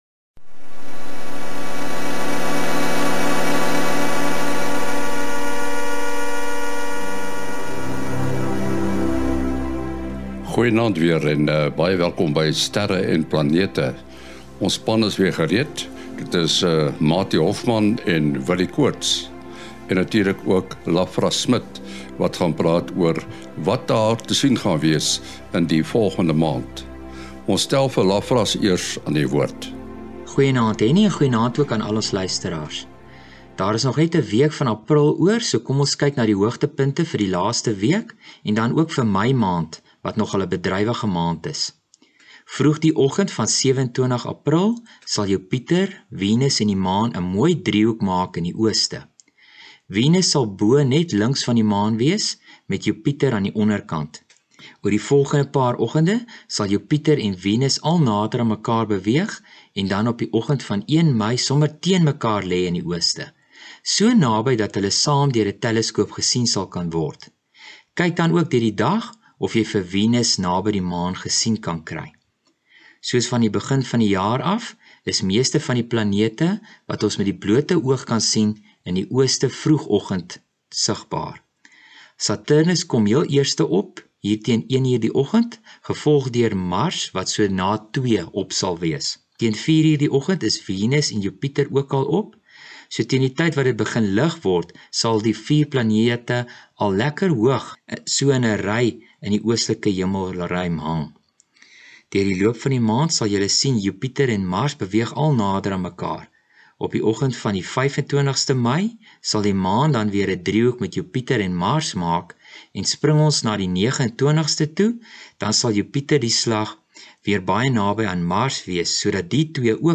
Daar is ook klank wat deur die mikrofone op die Endevour-marstuig opgeneem is.